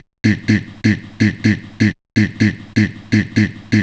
cch_vocal_dig_125.wav